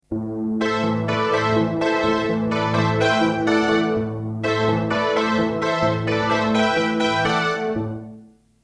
A great and unique "revival" soundbank including typical but original digital FM synthesis patches - Click here to read a detailed patches description
IMPORTANT NOTE: slight external reverb and chorus FXs have been added for the MP3 demos